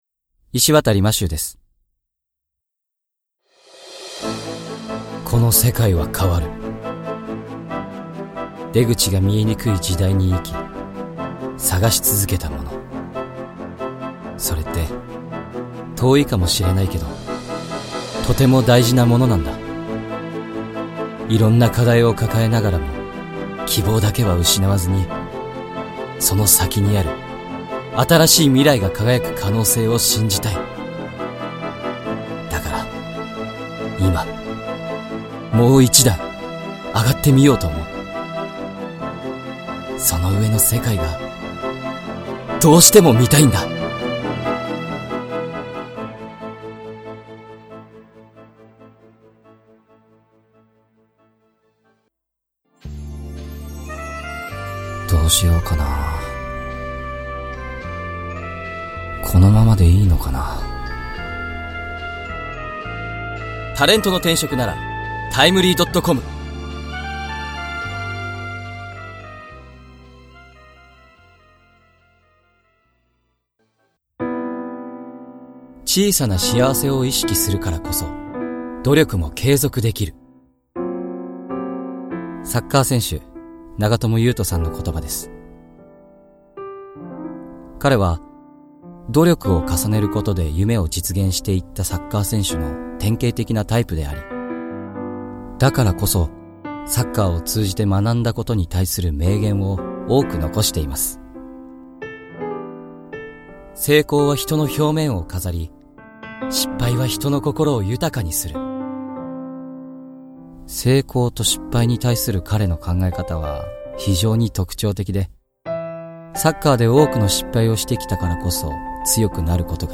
明るく柔らかい＆ 低くかっこいい声